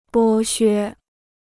剥削 (bō xuē): การใช้ประโยชน์; การเอาเปรียบ.